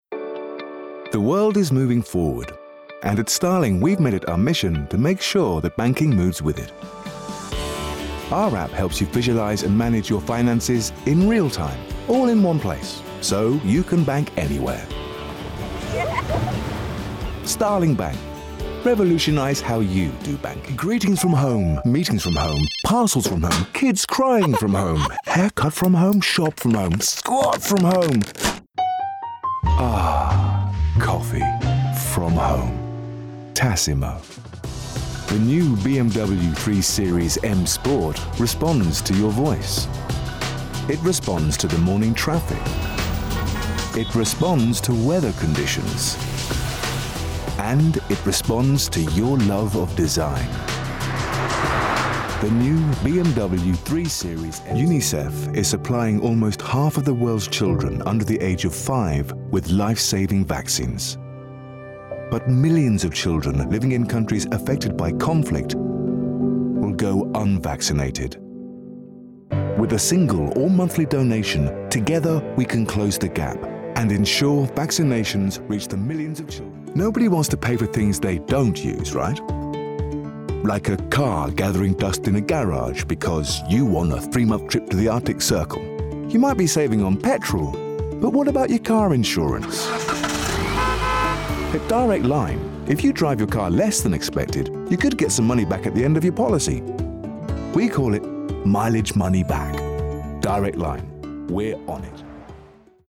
Male Artists (current)
RP. Confident, trustworthy, sincere, warm and friendly.